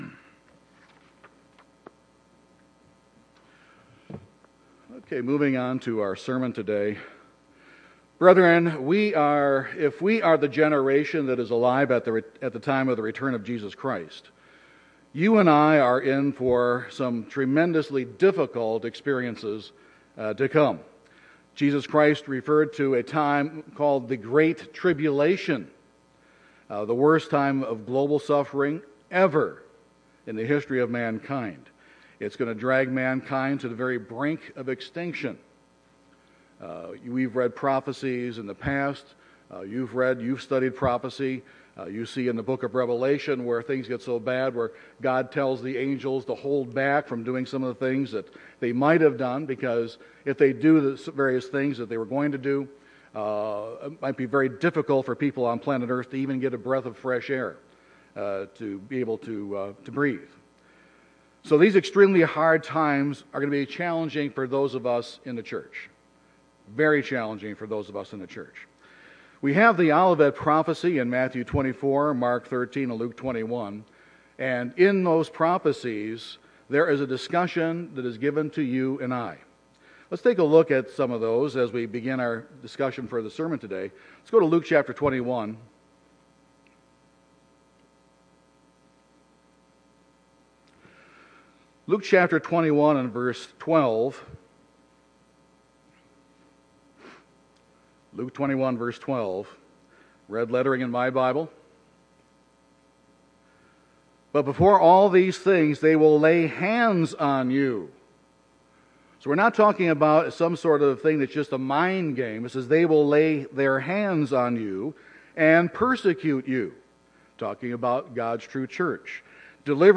This sermon discusses how we can make the proper spiritual preparations.